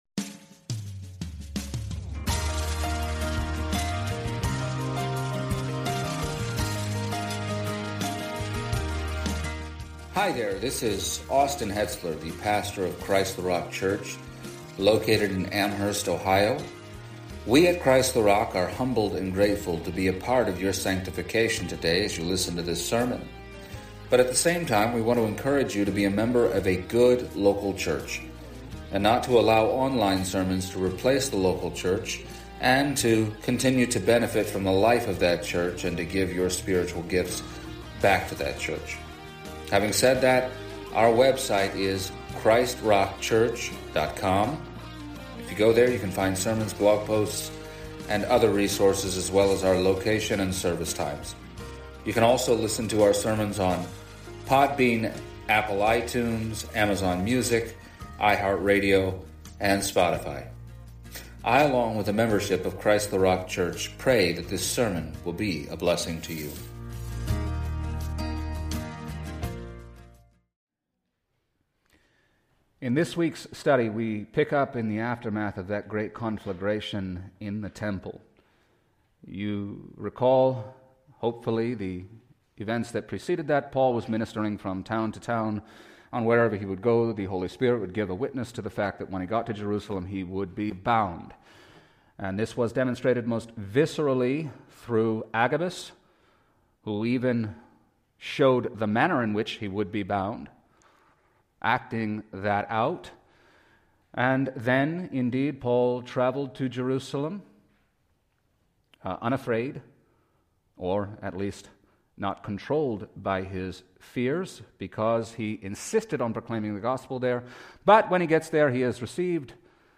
2024 I’m Telling You For the Last Time Preacher